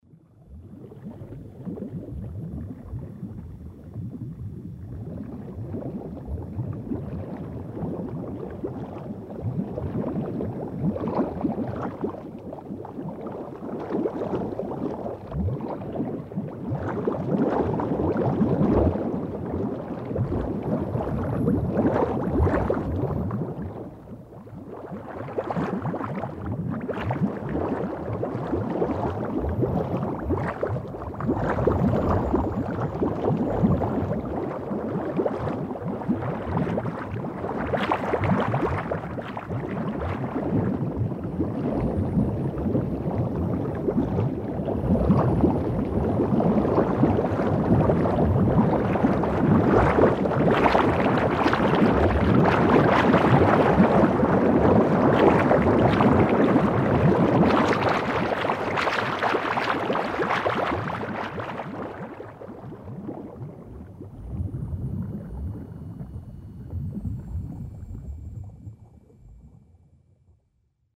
Звуки пузырьков
На этой странице собраны разнообразные звуки пузырьков: от легкого бульканья до интенсивного шипения газировки.
Шум бурлящей воды